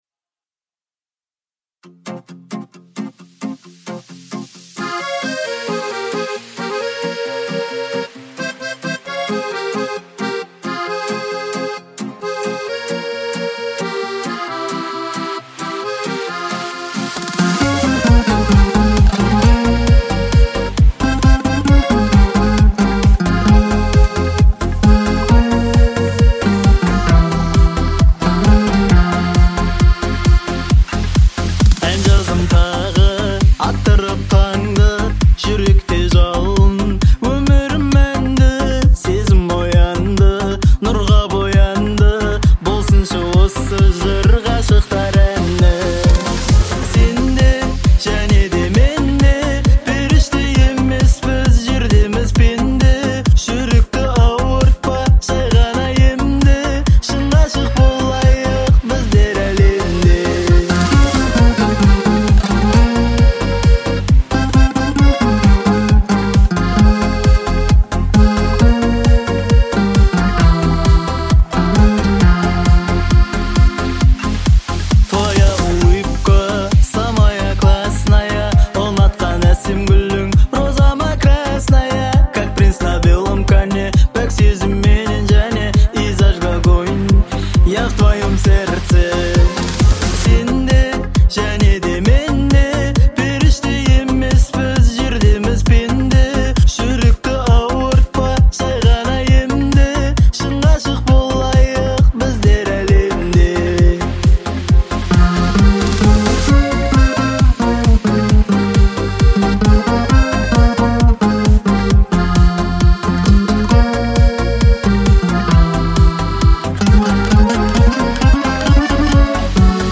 Трек размещён в разделе Казахская музыка.